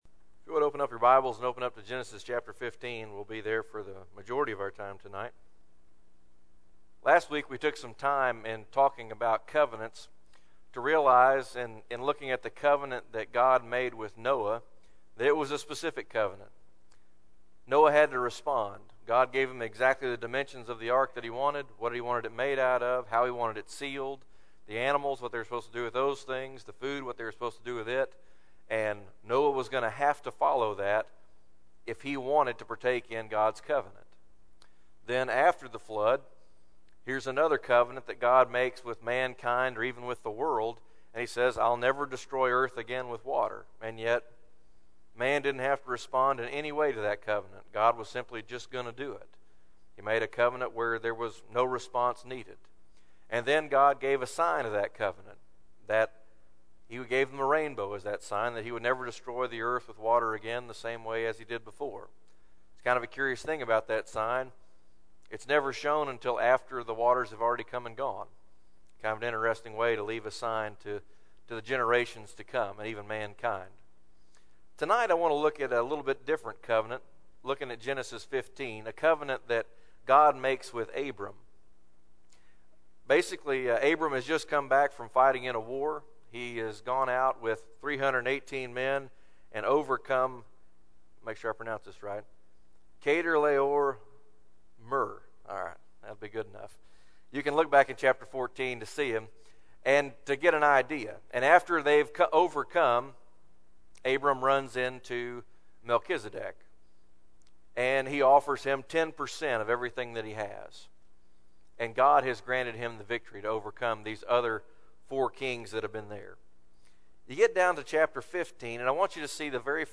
2025 Sermons CURRENT